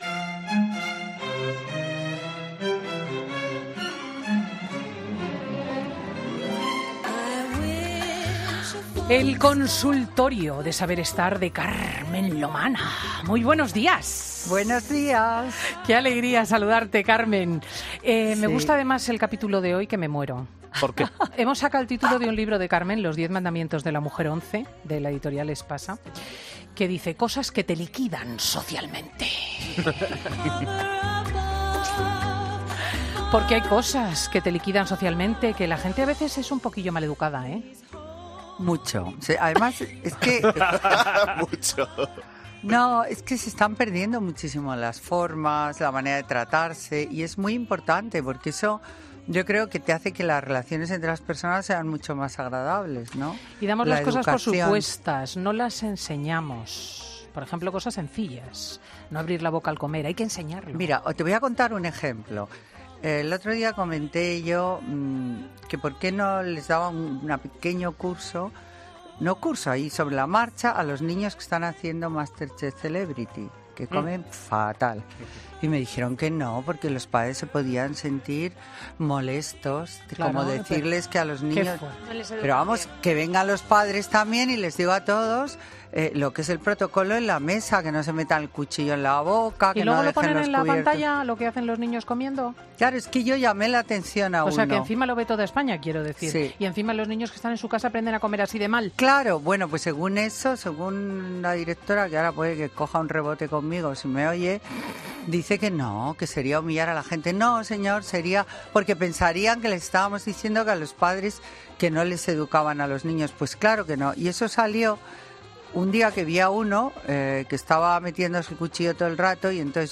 Presentado por Cristina López Schlichting, prestigiosa comunicadora de radio y articulista en prensa, es un magazine que se emite en COPE , los sábados y domingos, de 10.00 a 14.00 horas, y que siguen 769.000 oyentes , según el último Estudio General de Medios conocido en noviembre de 2017 y que registró un fuerte incremento del 52% en la audiencia de este programa.